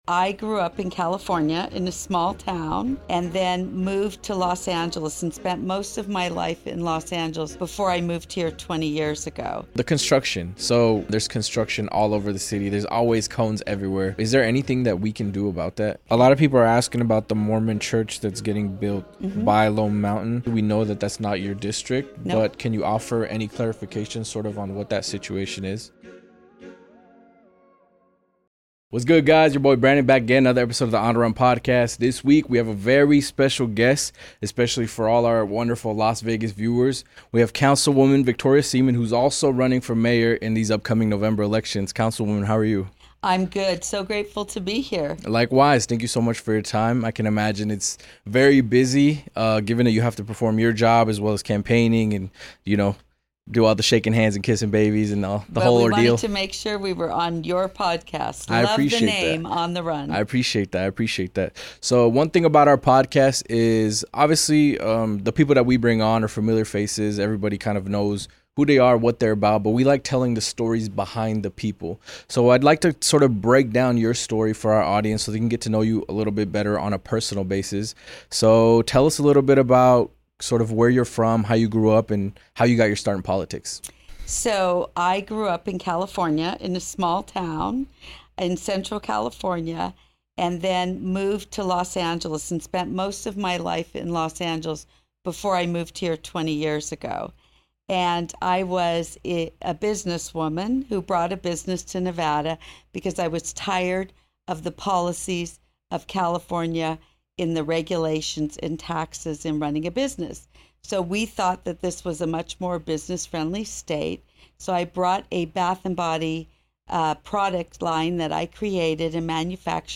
On this episode of On the Run , we sit down with Victoria Seaman , a councilwoman and a candidate in the upcoming Las Vegas mayoral race. Victoria shares her vision for the future of Las Vegas, tackling major issues like the city's ongoing construction projects , managing rapid city growth , and addressing the controversial Mormon church development in Lone Mountain .